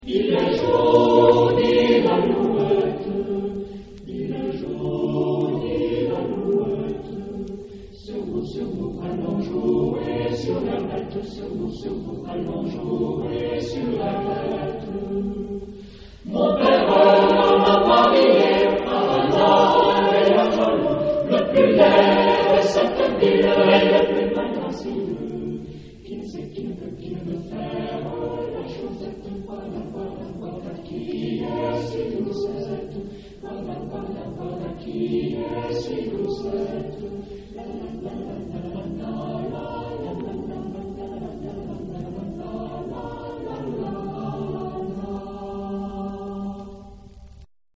Genre-Style-Form: Renaissance ; Partsong ; Secular
Mood of the piece: fast
Type of Choir: SATB  (4 mixed voices )
Tonality: D mixolydian